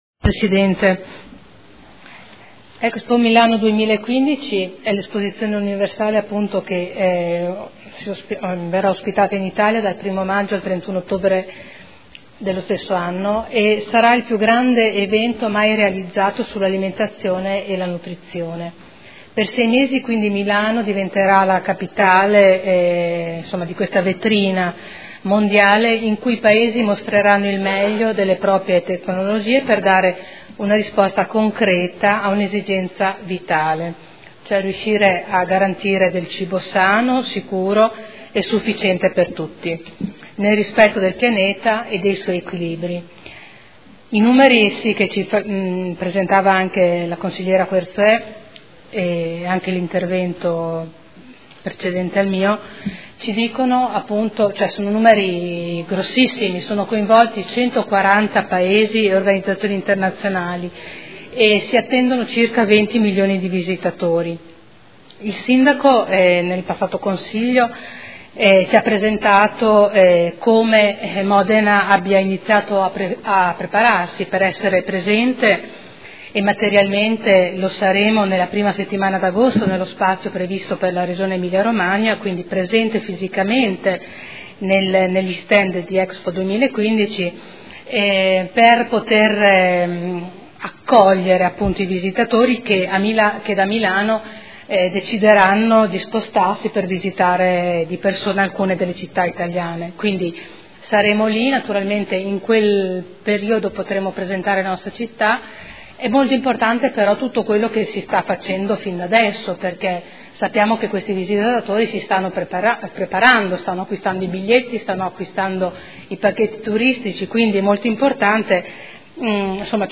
Seduta del 09/02/2015. Dibattito sugli ordini del giorno/mozioni inerenti l'expo 2015